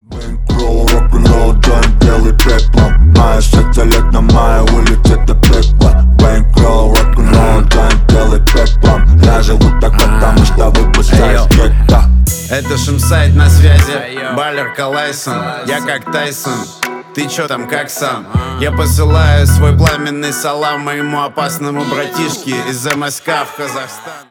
басы
рэп